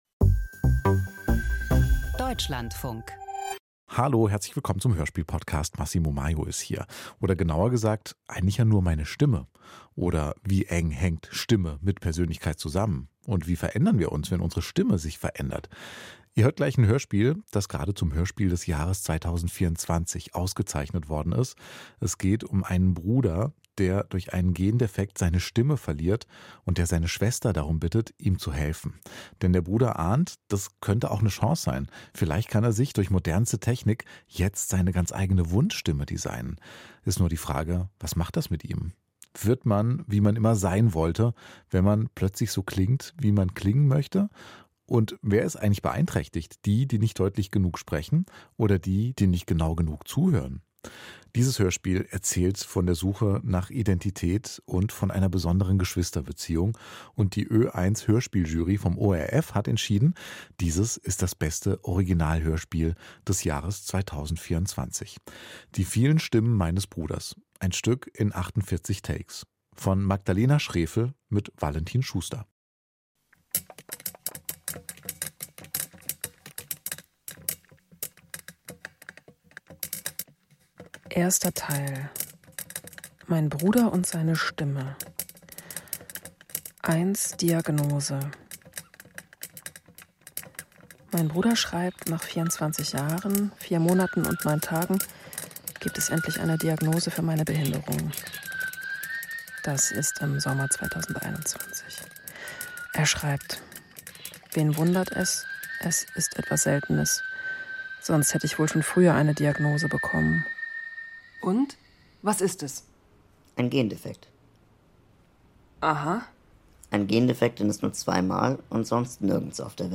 Hörspiel Archiv Hörspiel: Casting für künstliche Stimmen Die vielen Stimmen meines Bruders 57:11 Minuten Mit der Sprechmaschine von Wolfgang von Kempelen 1791 war der Grundstein für eine künstliche menschliche Sprache gesetzt.